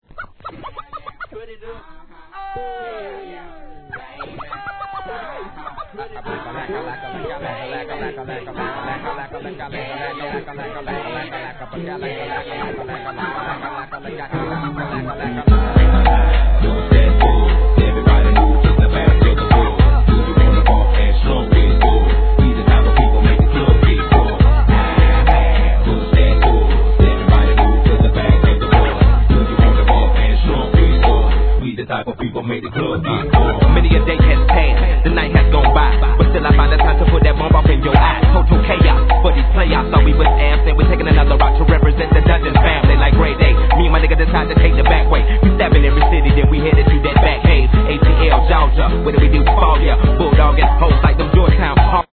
HIP HOP/R&B
一度聴いたら耳から離れない独特なビートと、ギターのリフが哀愁漂う中